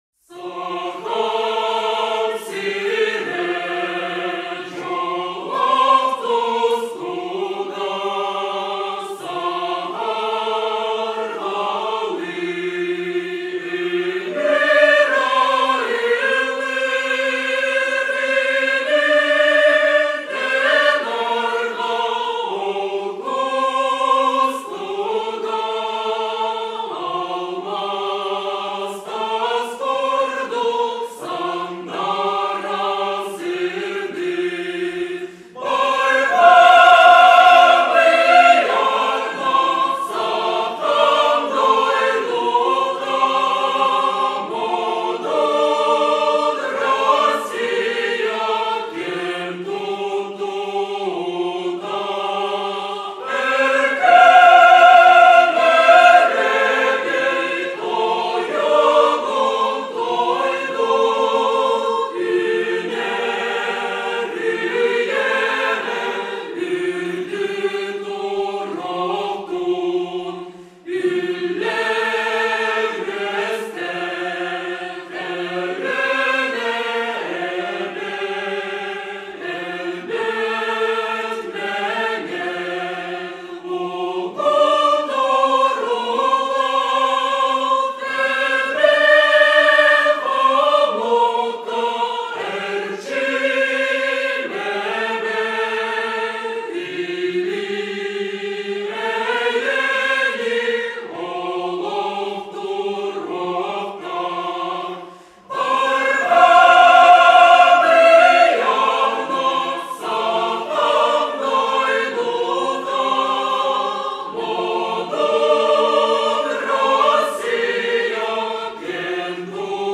Хор исполняет музыку без инструментов